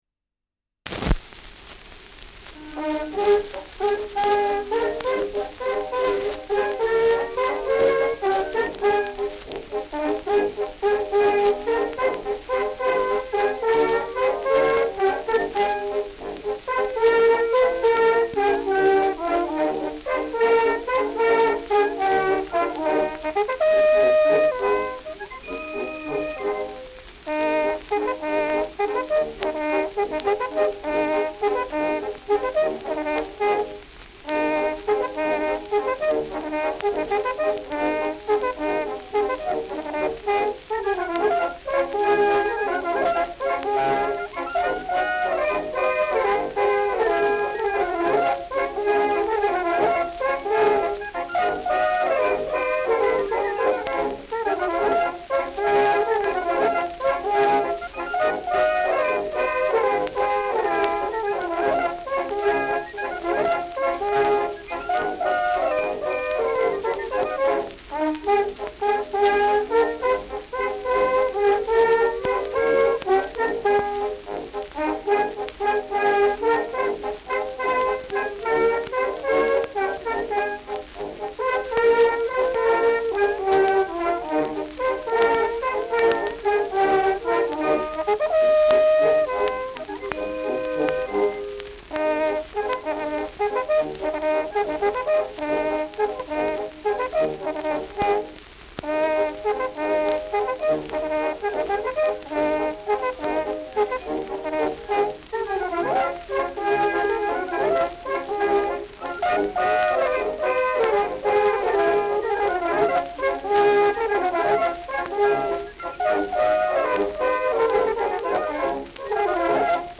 Schellackplatte
präsentes Rauschen
Stadtkapelle Fürth (Interpretation)
Mit Signalen.